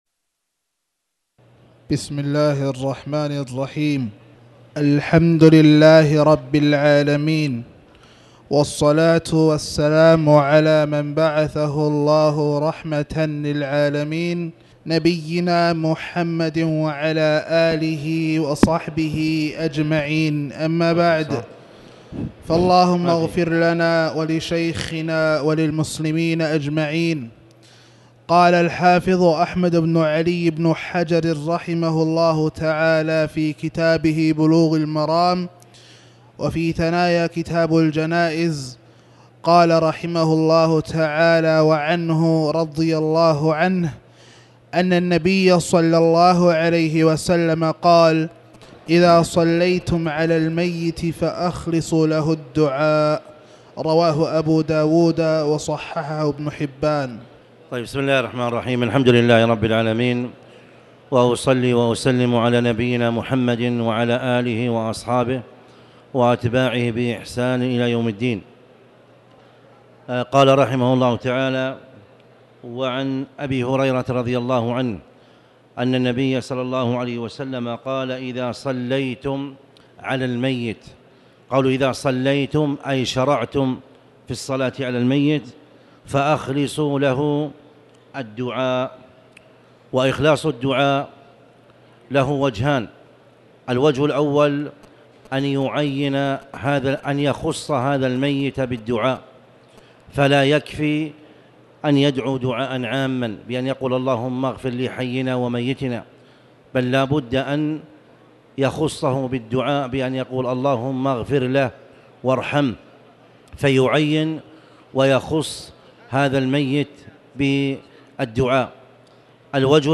تاريخ النشر ٣٠ جمادى الأولى ١٤٣٩ هـ المكان: المسجد الحرام الشيخ